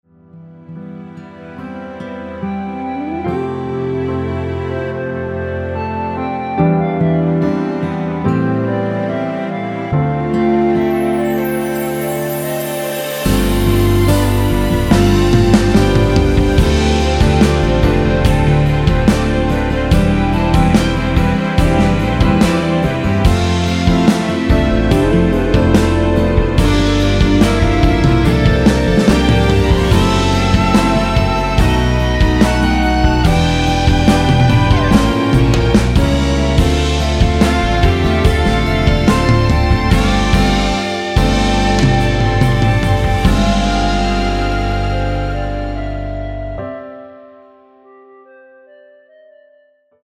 노래가 바로 시작 하는 곡이라 전주 만들어 놓았으며
원키 멜로디 포함된 1절후 후렴으로 진행되게 편곡한 MR 입니다.(미리듣기및 가사 참조)
멜로디 MR이라고 합니다.
앞부분30초, 뒷부분30초씩 편집해서 올려 드리고 있습니다.
중간에 음이 끈어지고 다시 나오는 이유는